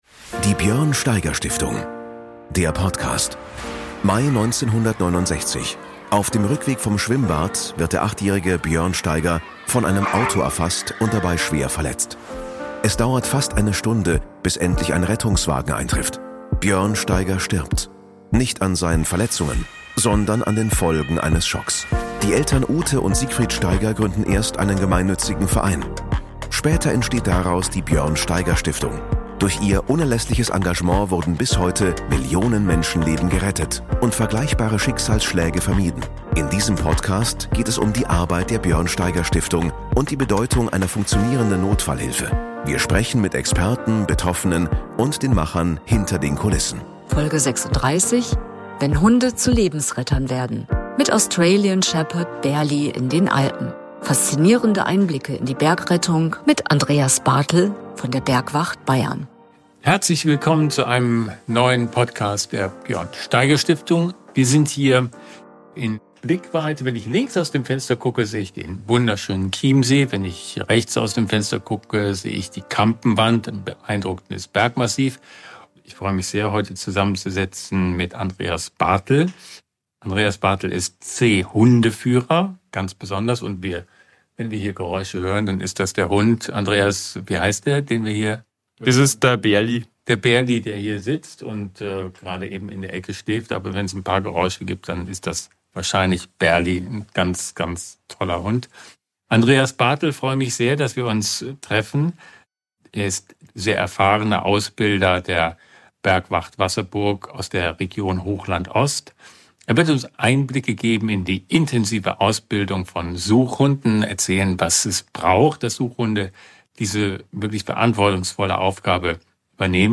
spricht mit Béla Anda über seine Arbeit mit dem ausgebildeten Lawinenhund Bärli. Was braucht ein Hund, um in Extremsituationen Leben zu retten? Wie läuft ein echter Lawineneinsatz ab – inklusive Hubschrauber, Winde und Suchtraining im Schnee?